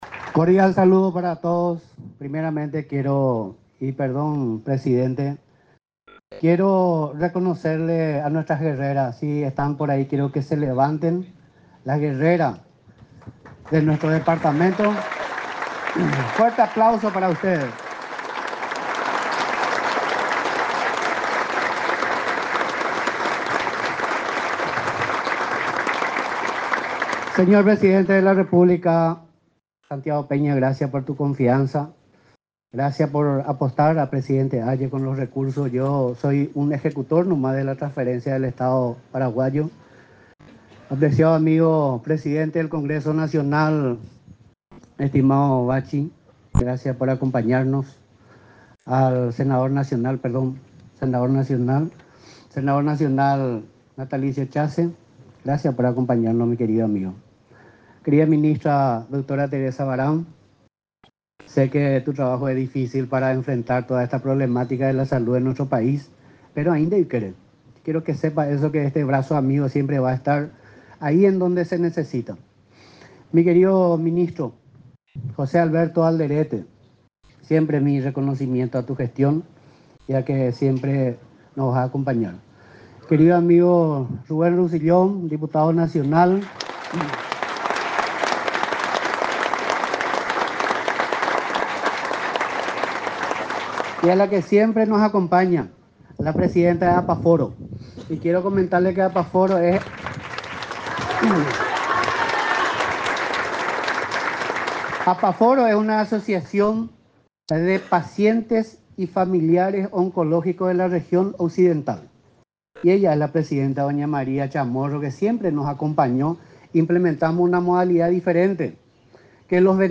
Entrevistas / Matinal 610
Escuchamos las palabras de Bernado Zárate (Gobernador de Presidente Hayes) respecto a la inauguración pabellón oncológico en el Hospital Regional de Villa Hayes.
Entrevistado: Bernardo Zarate